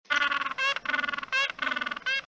Penguin Bouton sonore